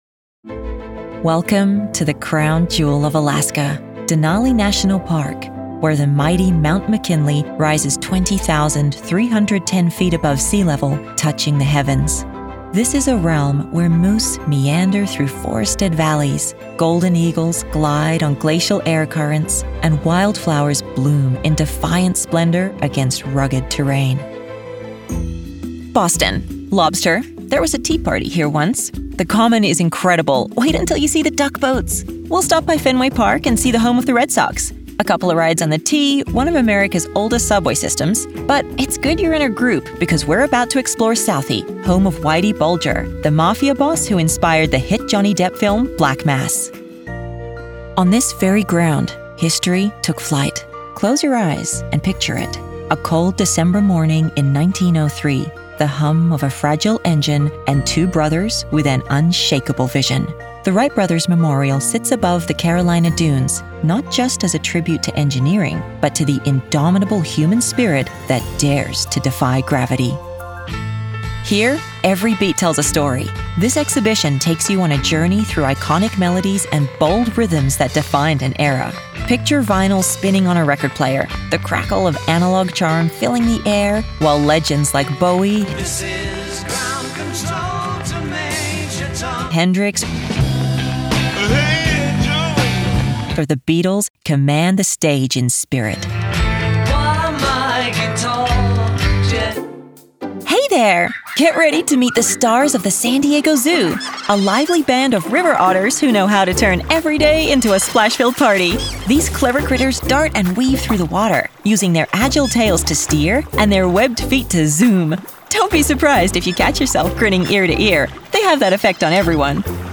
Warm, confident, nuanced, and with range from deep luxury to sarcastic milennial and everything in between.
Museum Tours & Audio Guides
General American, Neutral Canadian
Young Adult
Middle Aged